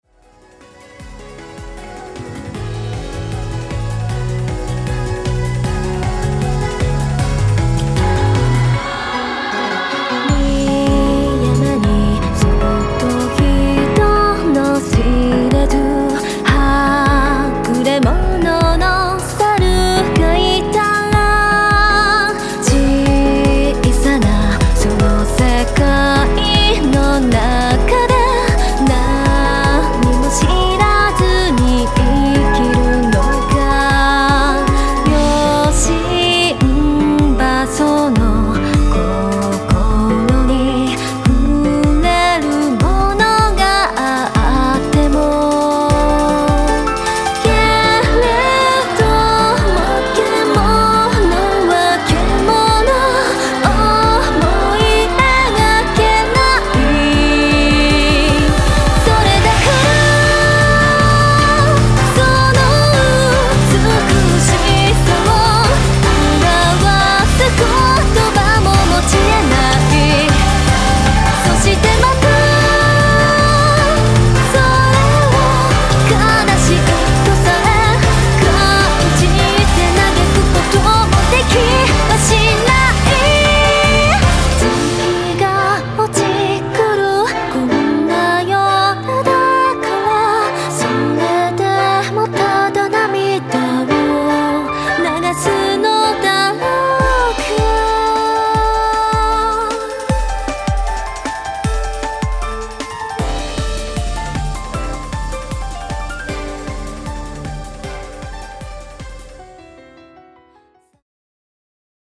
東方マルチジャンルアレンジアルバムです。
Vocal